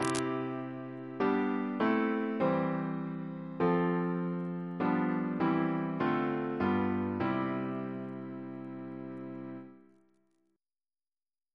Single chant in C Composer: Jonathan Battishill (1738-1801) Reference psalters: ACB: 190; CWP: 129; H1940: 666; OCB: 56; PP/SNCB: 115; RSCM: 168